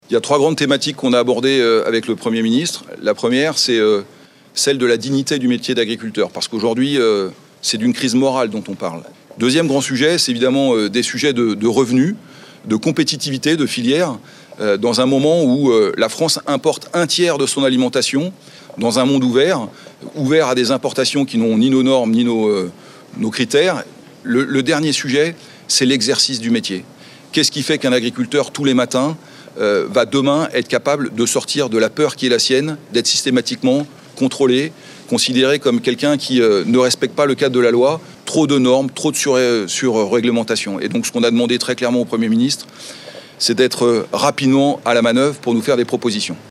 Le président de la FNSEA, Arnaud Rousseau, a pu prendre la parole à la suite de la réunion.